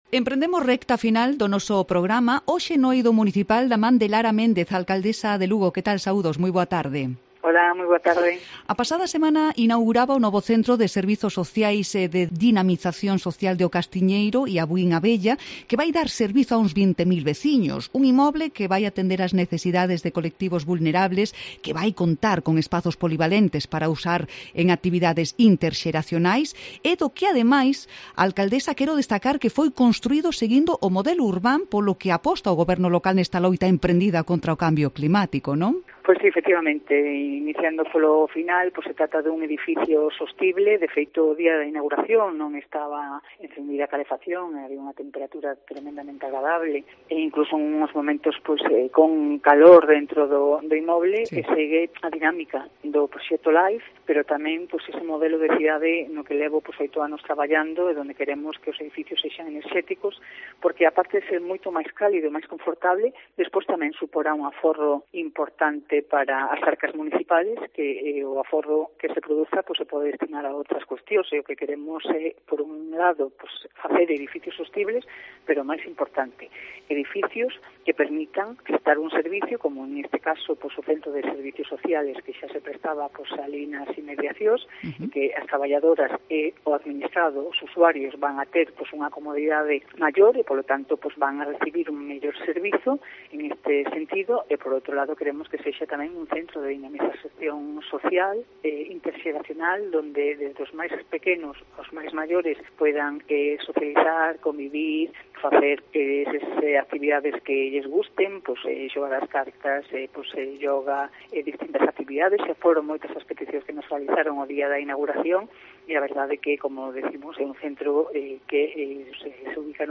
Entrevista a Lara Méndez en Cope Lugo